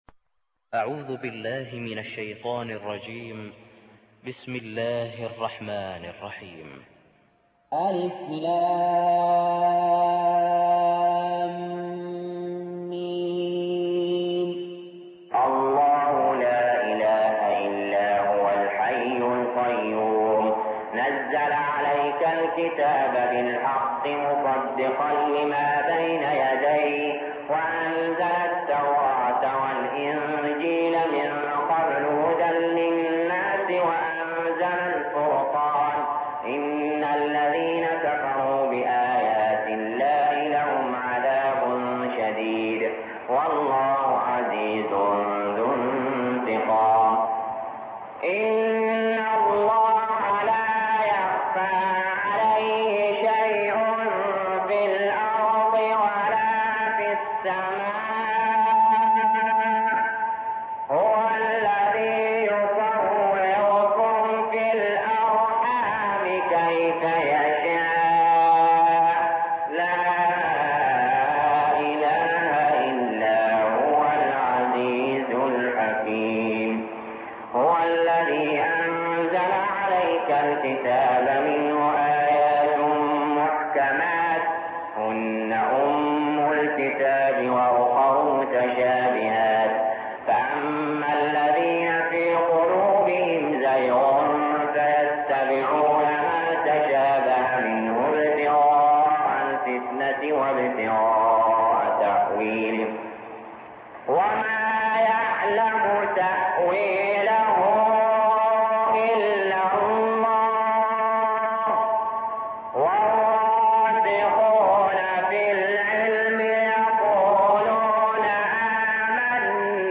المكان: المسجد الحرام الشيخ: علي جابر رحمه الله علي جابر رحمه الله آل عمران